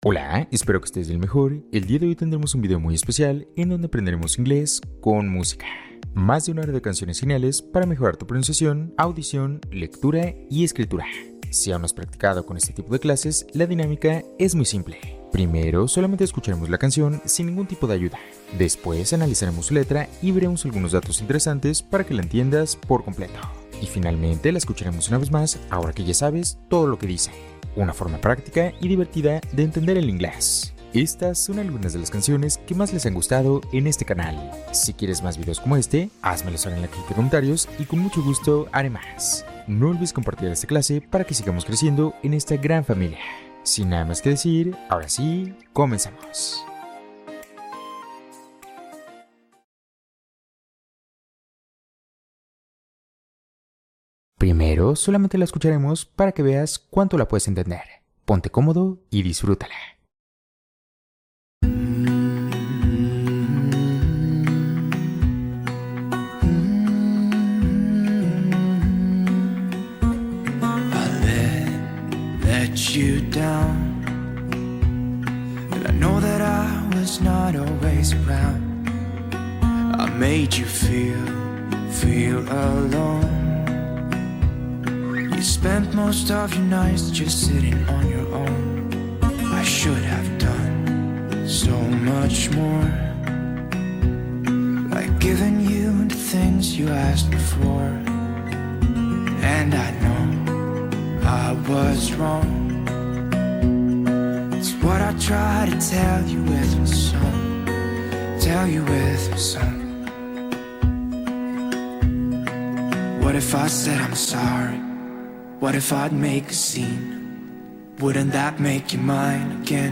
Programa auditivo con música para reforzar tu aprendizaje del inglés